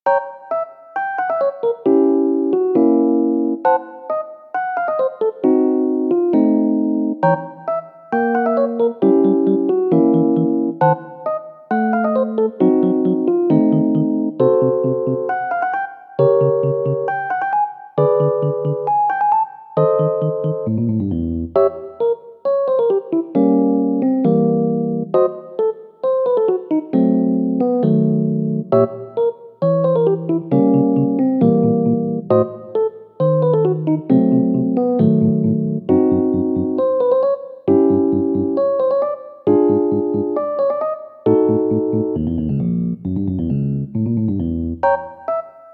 イメージ：不安 エレピ   カテゴリ：ユニーク・怪しい